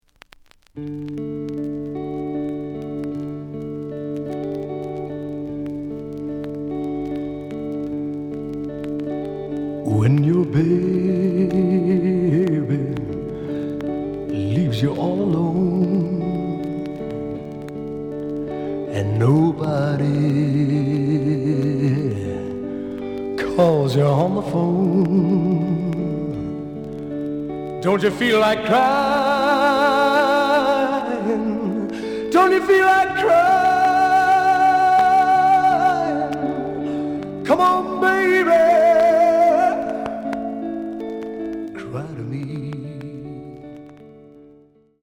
The audio sample is recorded from the actual item.
●Genre: Rock / Pop
Some click noise on both sides due to scratches.